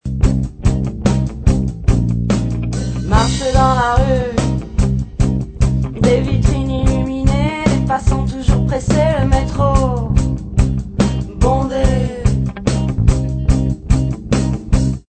rock alternatif